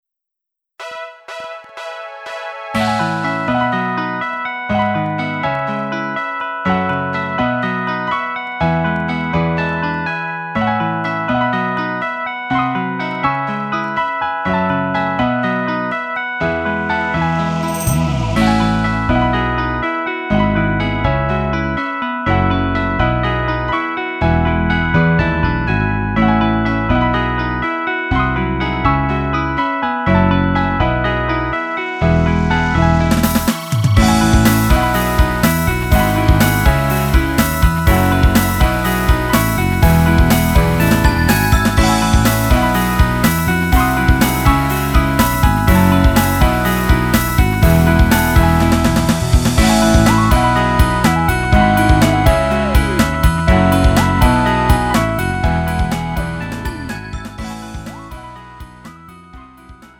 음정 -1키 3:43
장르 가요 구분